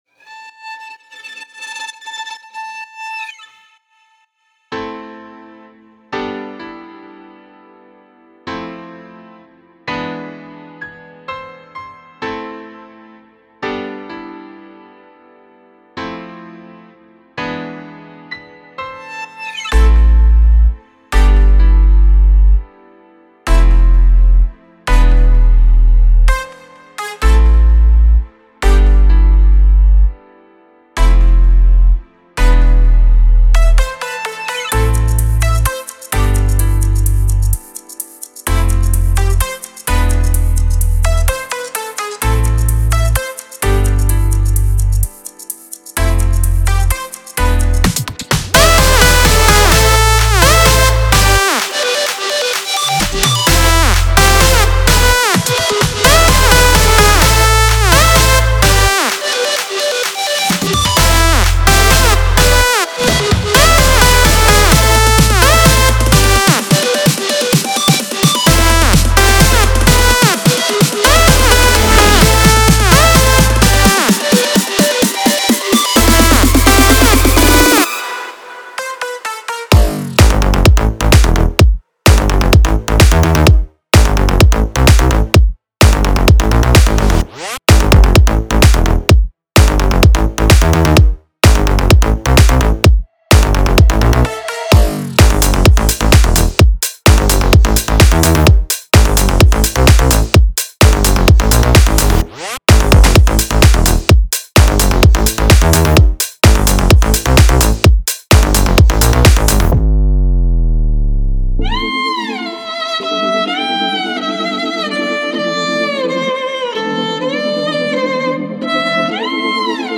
ובאמת הכינור ממש מטורף איזה פלאגין זה באמת?